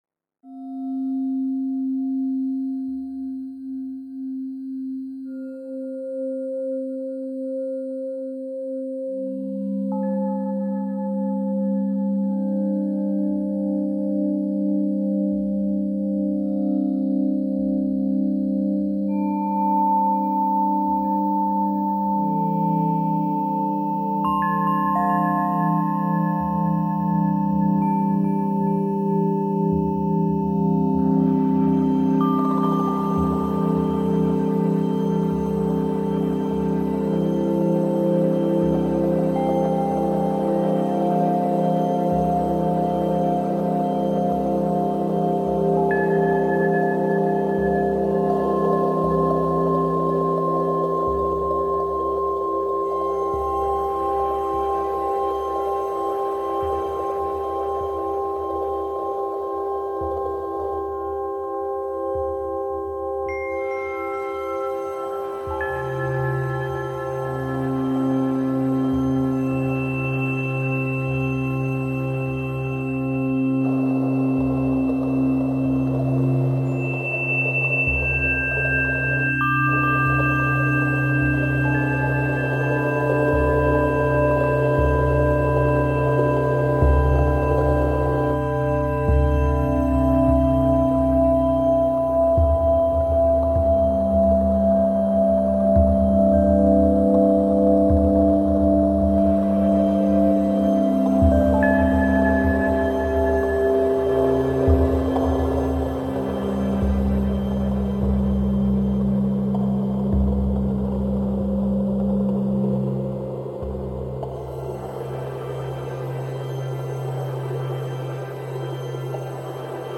This piece features two chimes, two tone cups and a balafon